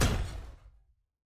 Footstep Robot Large 2_08.wav